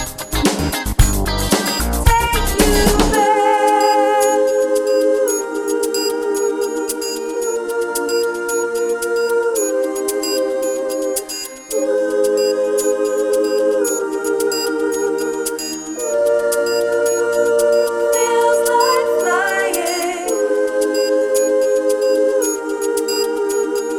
Two Semitones Down Pop (1980s) 5:29 Buy £1.50